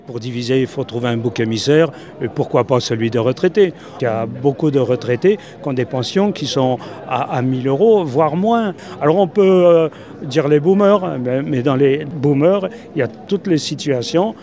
Étudiants, fonctionnaires, retraités, ouvriers : Ils étaient environ un millier à s’être réunis hier pour la manifestation à Mende dans le cadre du mouvement national de grève du 18 septembre.